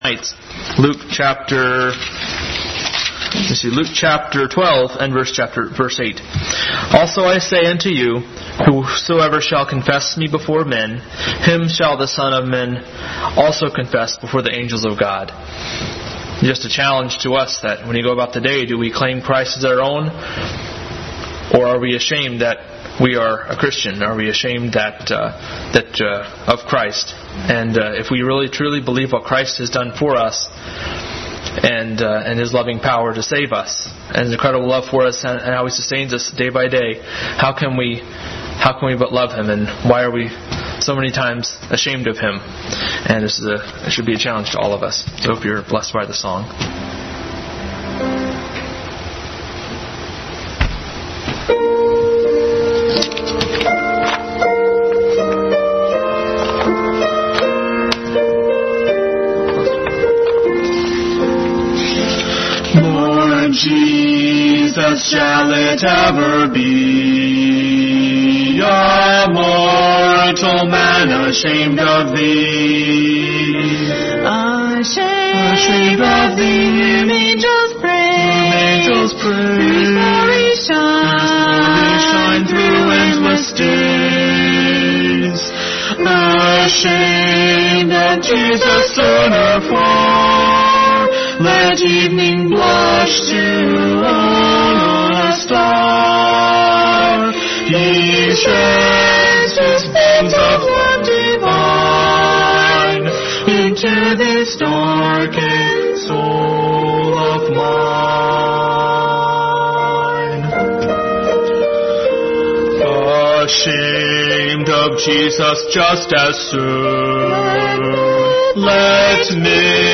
Special Music – 9/22/2019 – Ashamed of Jesus?
Duet